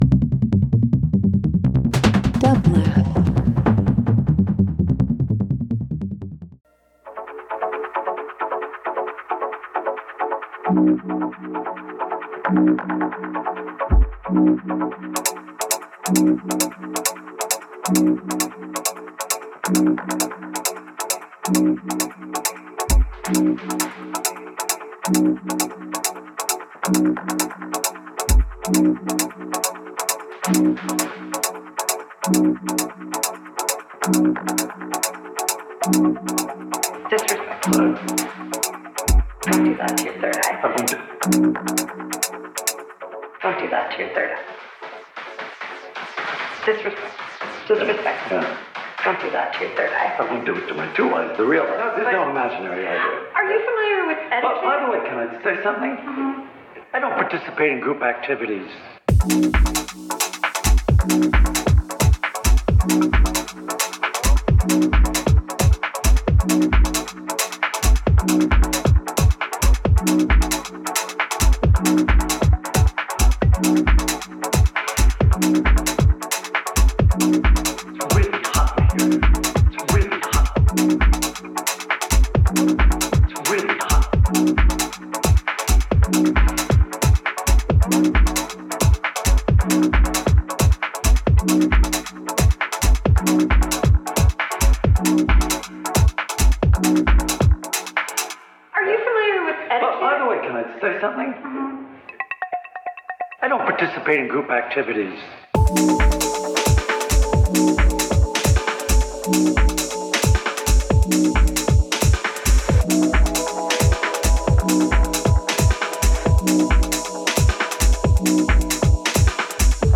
Dance Electronic Experimental Indian